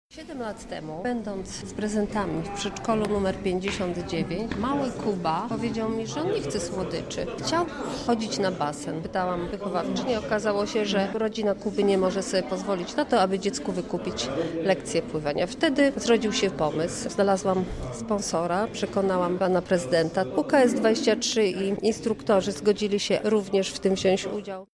O powodach przygotowania tego programu mówi jego pomysłodawczyni, wiceprzewodnicząca rady miasta, Marta Wcisło